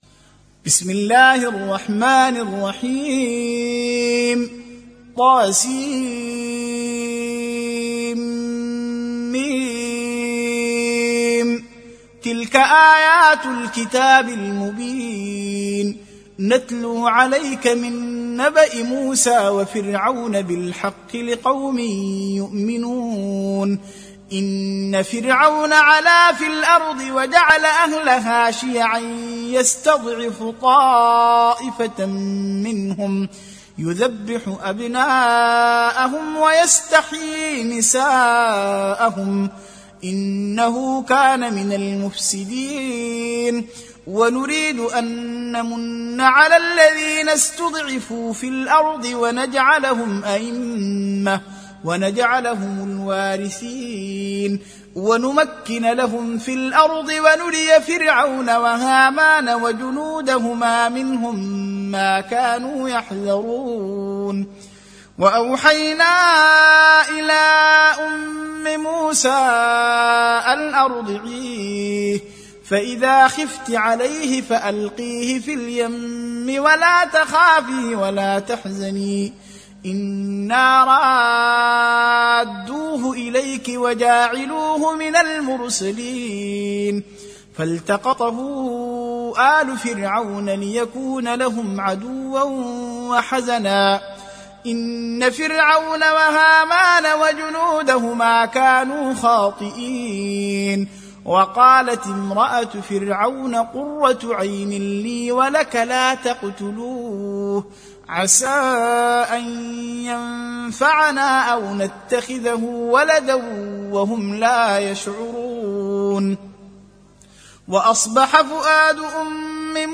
28. Surah Al-Qasas سورة القصص Audio Quran Tarteel Recitation
Surah Repeating تكرار السورة Download Surah حمّل السورة Reciting Murattalah Audio for 28.